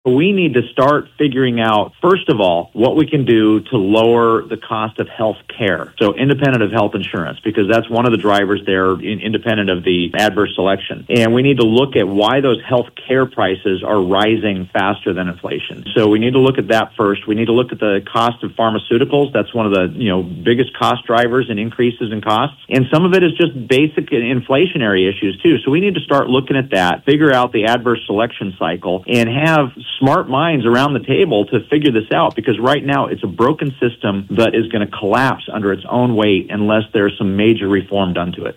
Montana Congressman Troy Downing shared today on Voices of Montana the steps needed to address the skyrocketing cost of Health Insurance.
MT Congressman Troy Downing